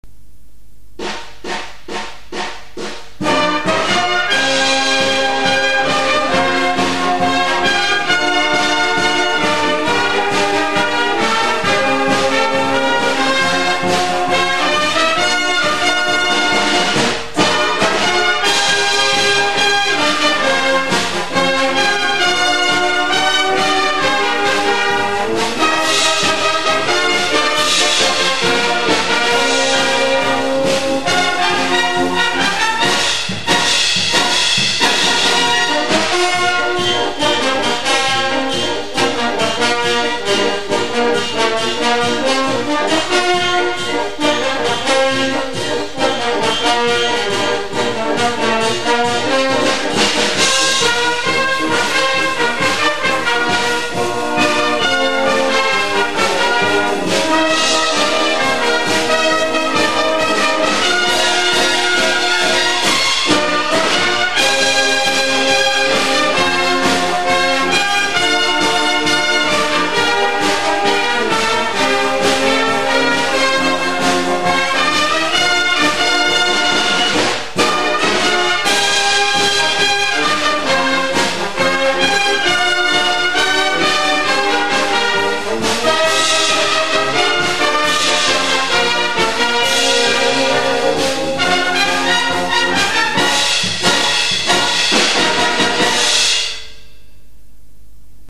合奏を録音したMP3ファイルです。
2000年秋季リーグ戦のチア曲。録音は、かわちの体育館での初合奏です。